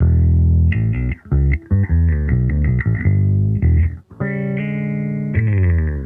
Index of /musicradar/sampled-funk-soul-samples/79bpm/Bass
SSF_JBassProc2_79E.wav